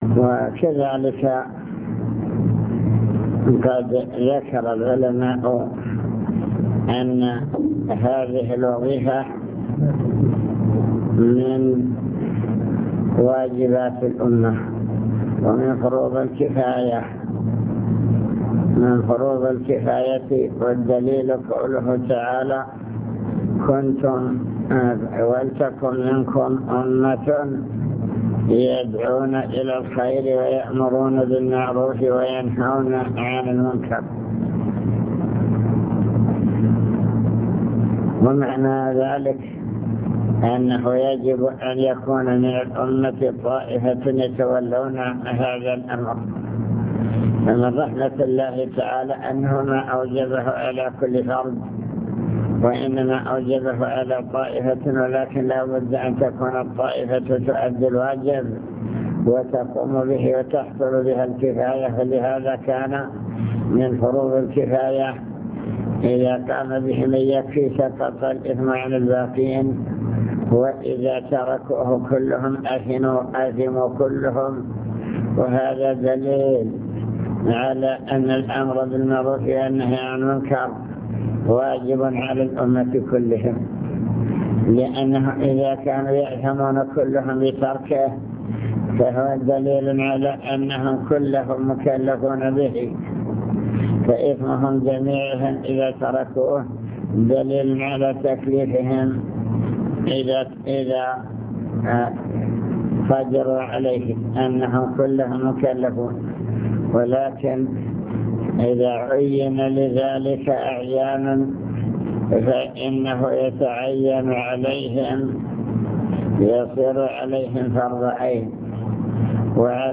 المكتبة الصوتية  تسجيلات - لقاءات  كلمة في رئاسة الأمر بالمعروف الدعوة إلى الله تعالى